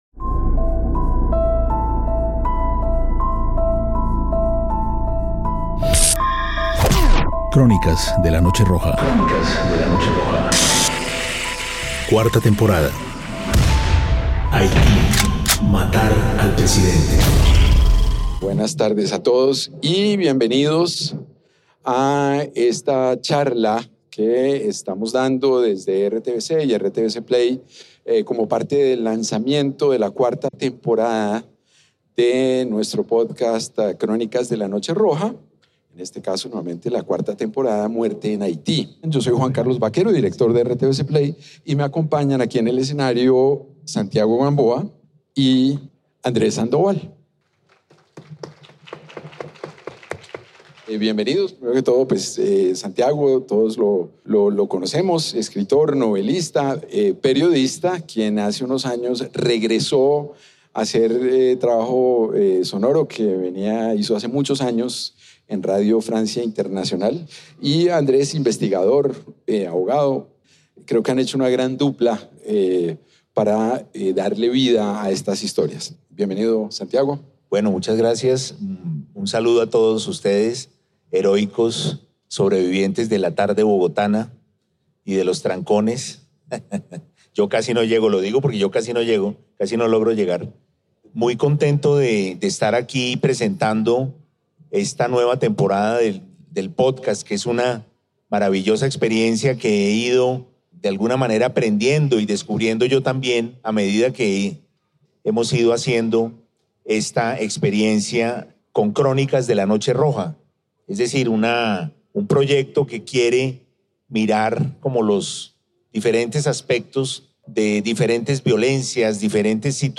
Conversación en la FILBO - Crónicas de la noche roja | RTVCPlay